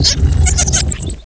pokeemerald / sound / direct_sound_samples / cries / crustle.aif